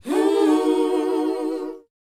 WHOA C#B U.wav